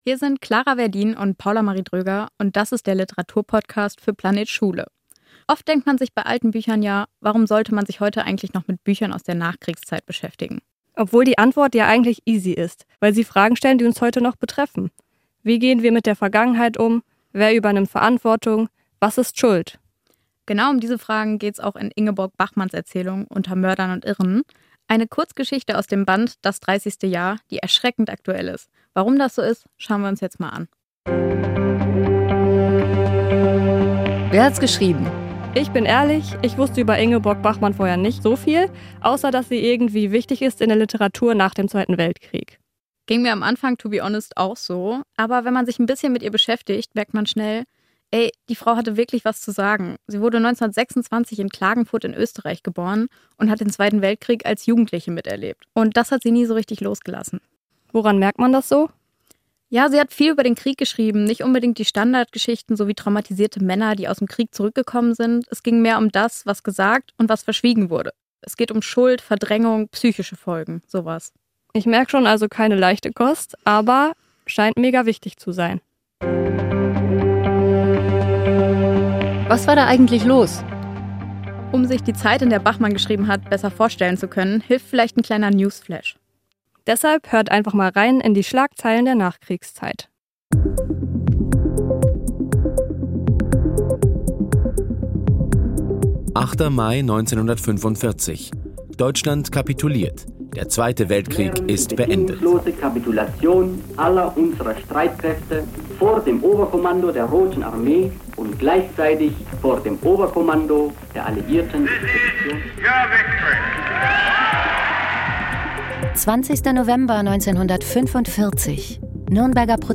Unsere beiden Hosts haben sich die Geschichte „Unter Mördern und Irren“ aus dem Buch „Das dreißigste Jahr“ vorgenommen.
• Einspieler: Schlagzeilen der Nachkriegszeit